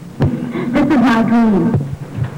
Guest EVPs
He had been asked by the police to assist  in  a murder investigation and captured it at the scene.